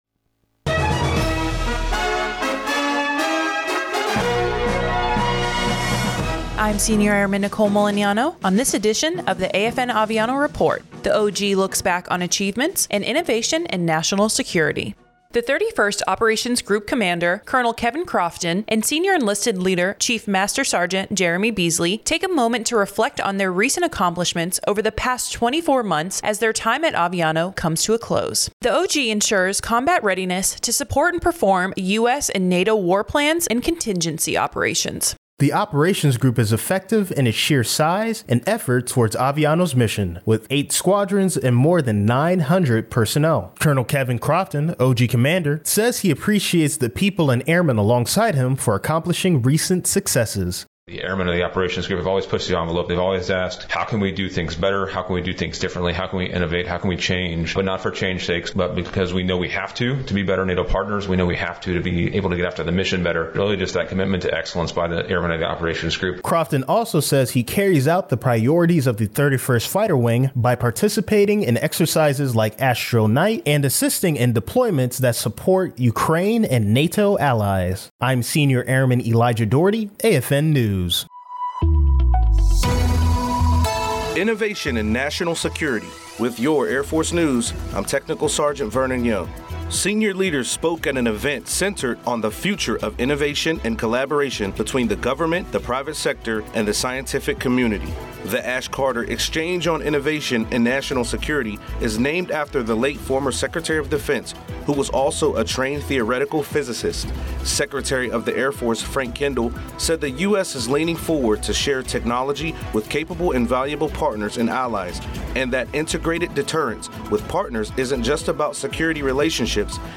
American Forces Network (AFN) Aviano radio news reports on recent accomplishments made by the 31st Operations Group.